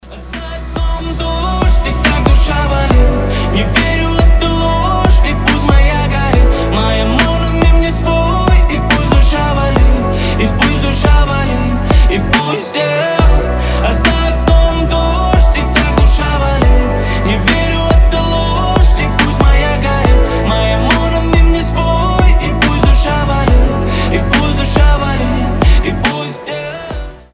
• Качество: 320, Stereo
лирика
мелодичные